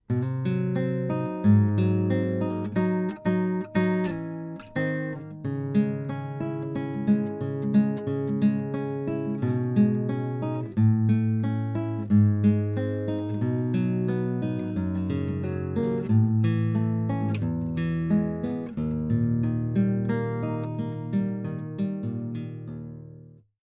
Guitar
Slow